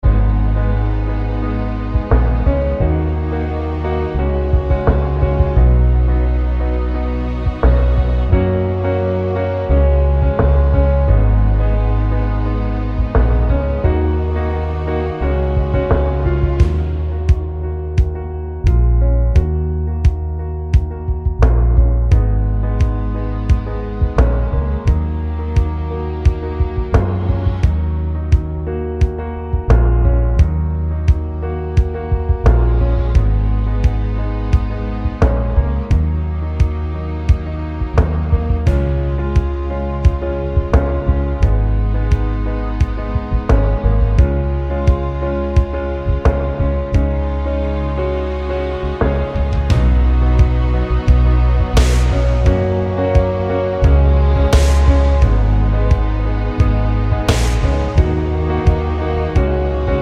no Backing Vocals or sample Pop (2010s) 3:50 Buy £1.50